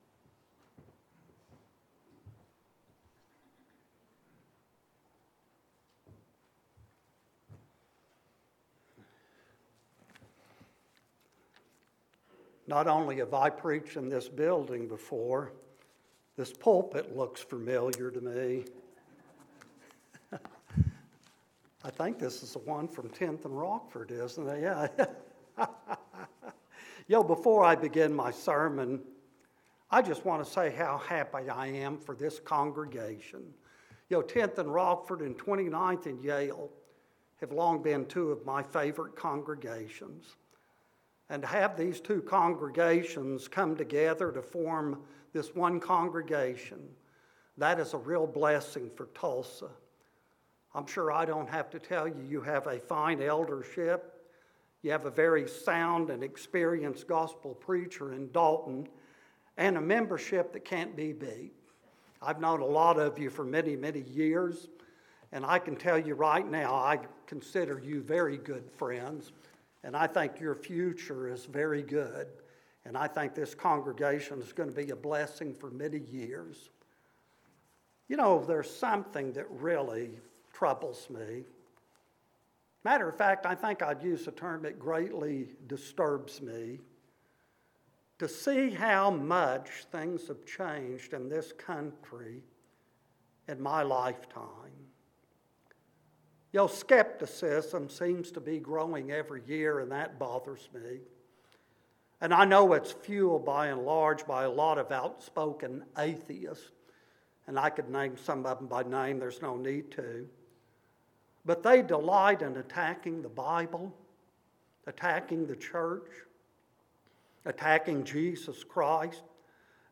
Scripture Reading